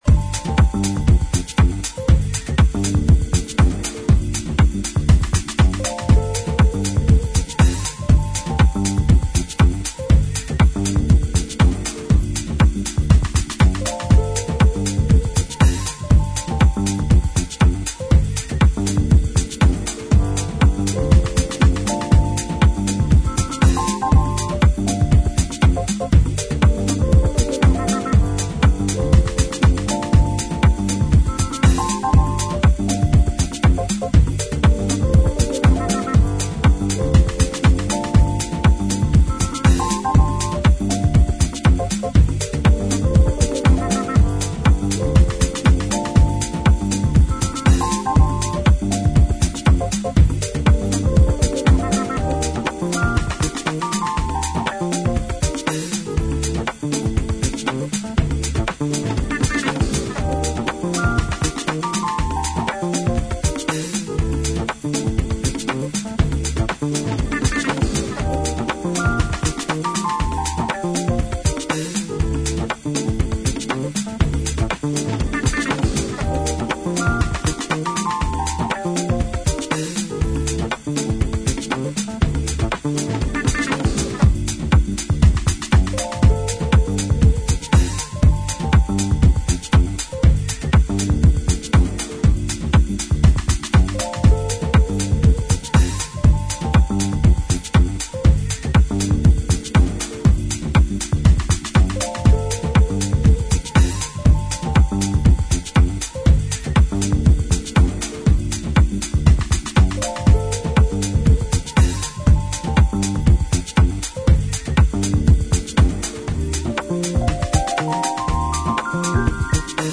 ジャンル(スタイル) HOUSE / DISCO / RE-EDIT